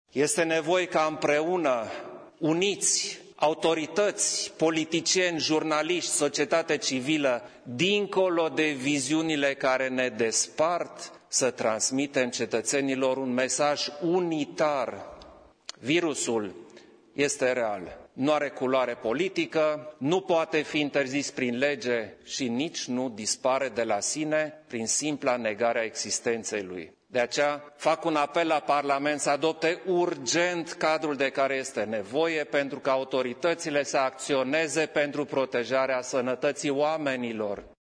Totodată, șeful statului a vorbit de urgenţa unui cadru legal, care să fie adoptat de Parlament: